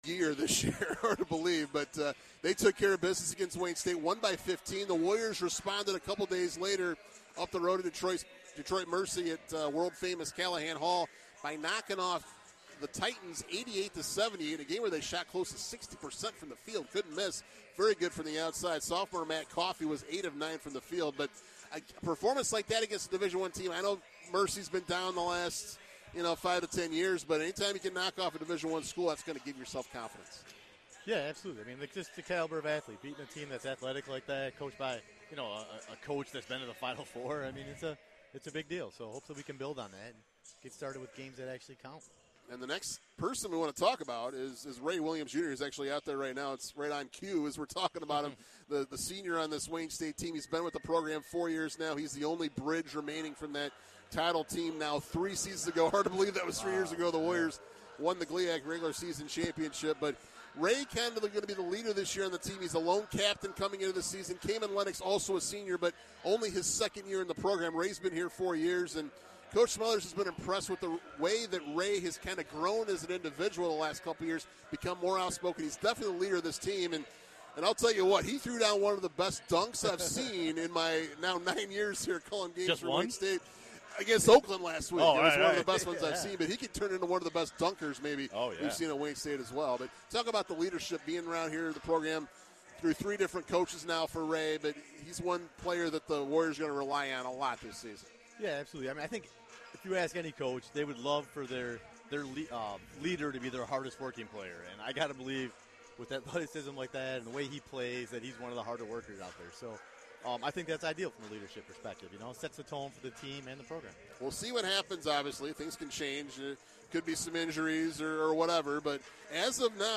Men's Basketball WDTK Broadcast vs. Hillsdale (Part 1)